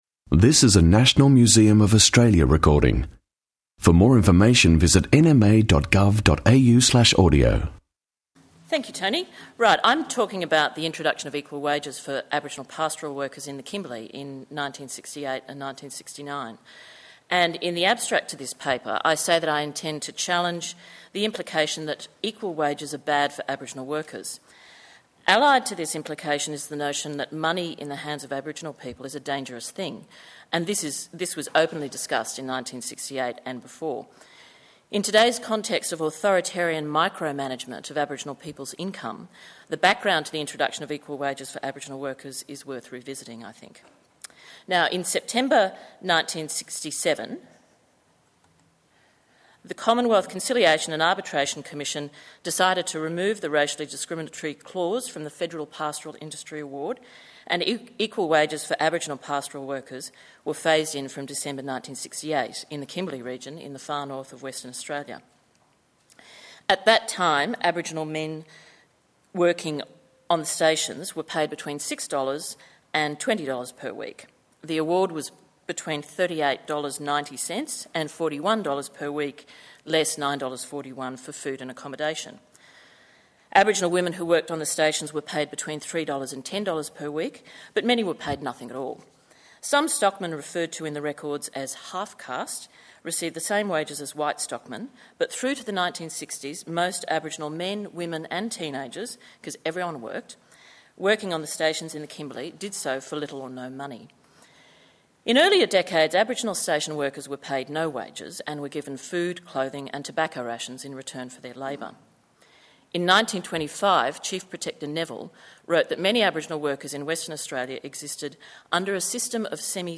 Indigenous Participation in Australian Economies conference 10 Nov 2009